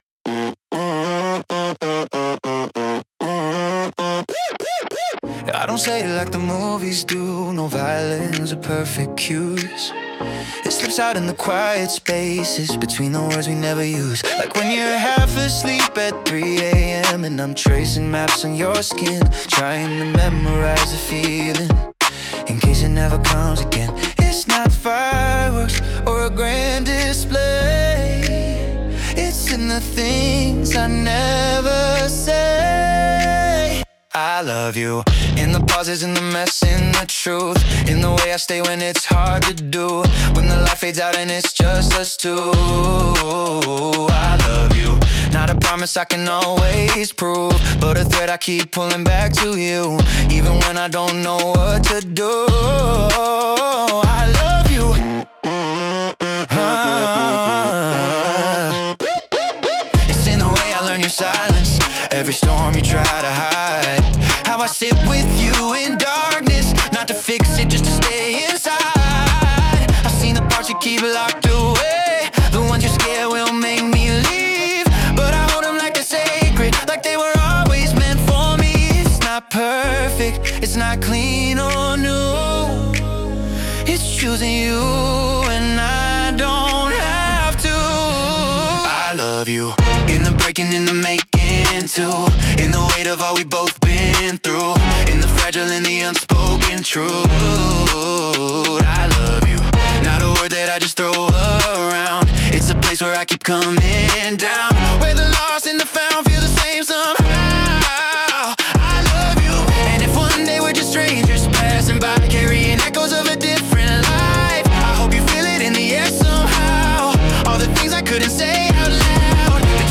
Pop 2026 Non-Explicit